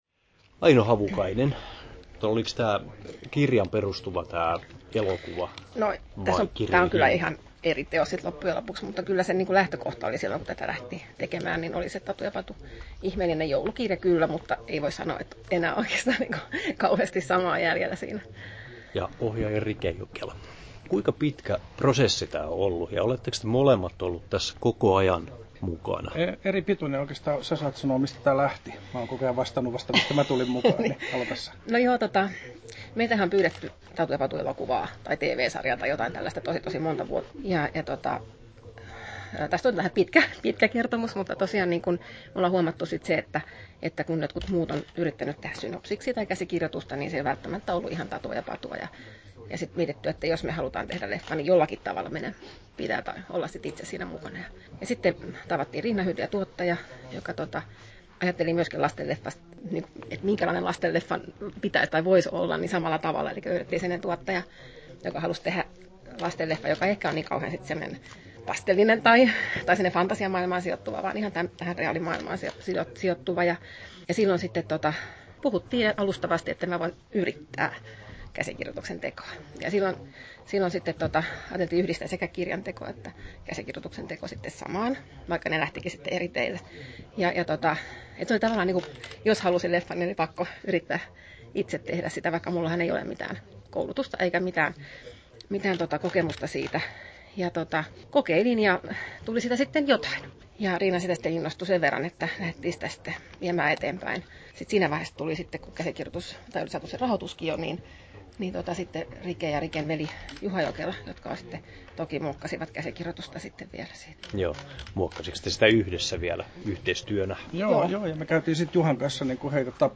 • Haastattelut
11'47" Tallennettu: 6.10.2016, Turku Toimittaja